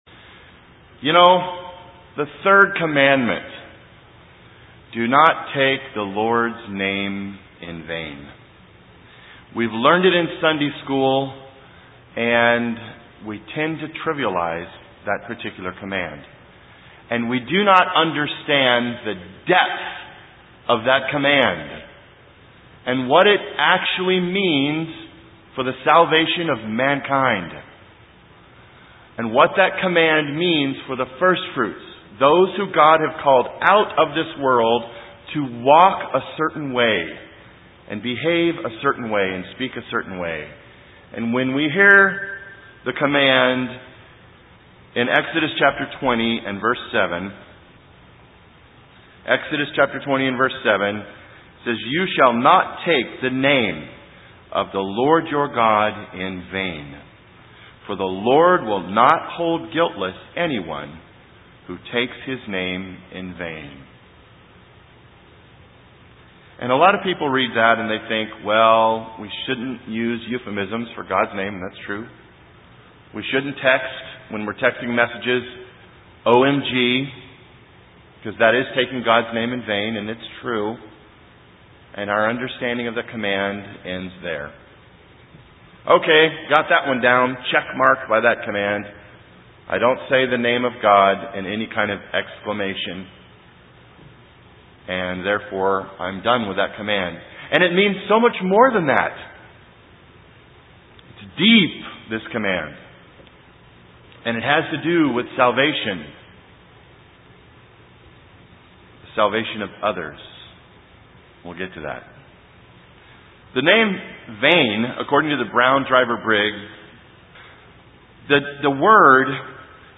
This sermon will help us understand the importance of why we do not take our Fathers name in vain.